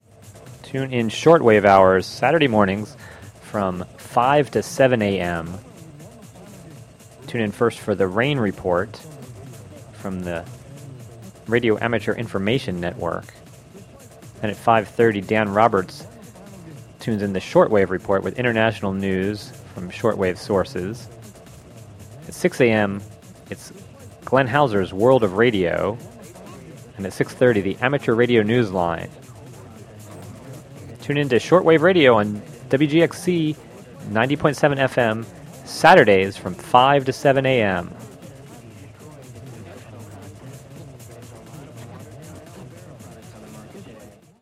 An Official PSA for "Shortwave Hours," amateur radio programming on WGXC Saturdays from 5-7 a.m. (Audio)